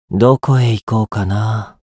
觉醒语音 何処へ行こうかなあ…